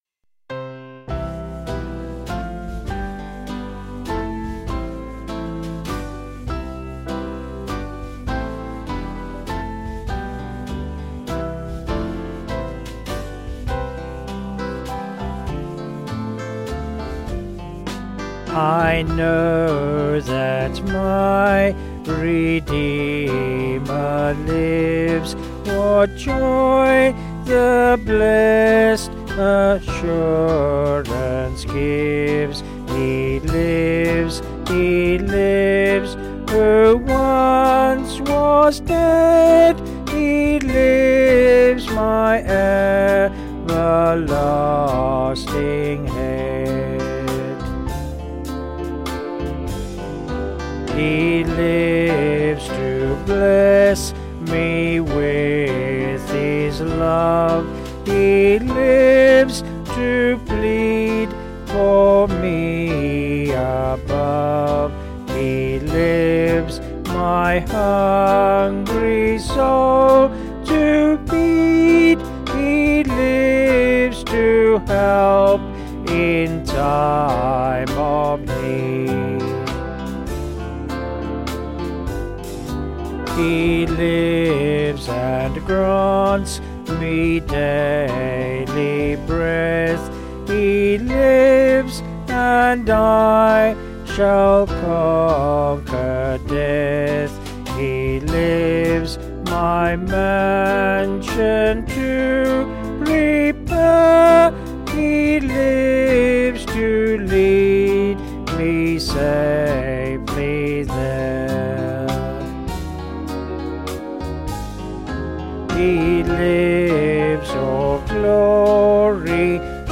Vocals and Band   705.9kb